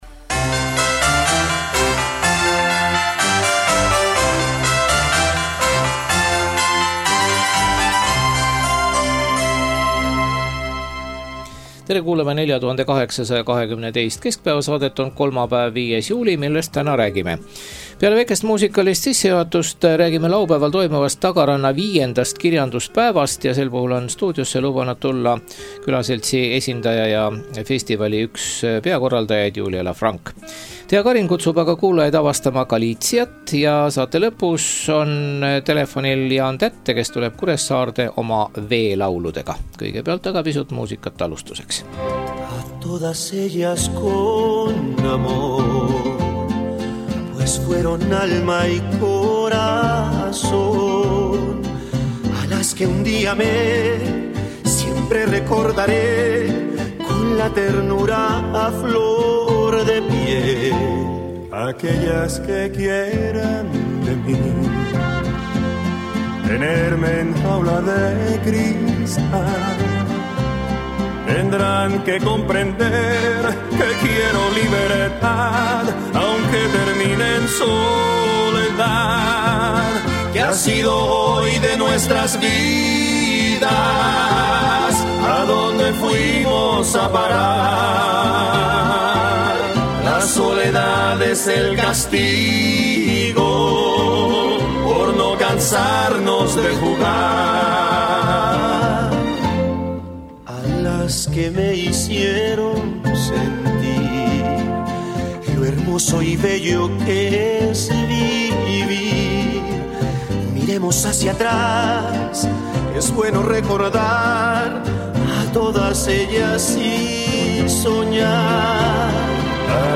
Laupäeval toimuvast Tagaranna V kirjanduspäevast räägib stuudios lähemalt Julia Laffranque.